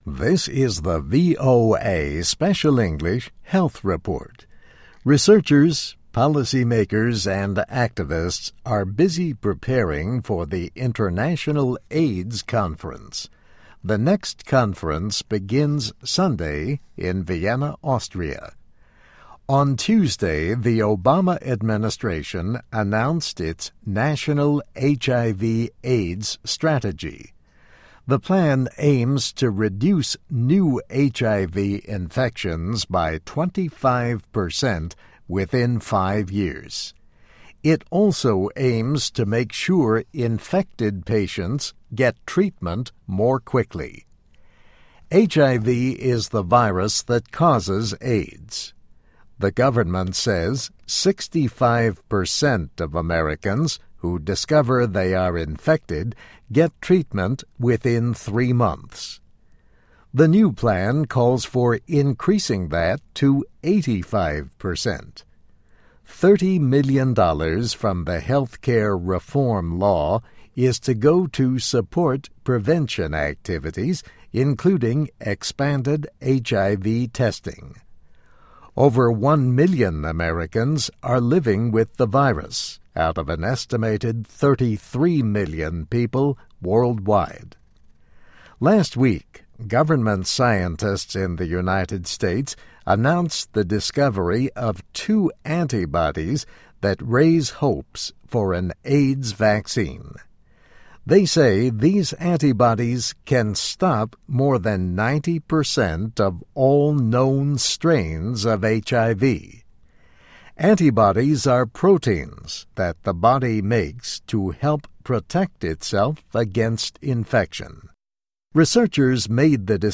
Health Report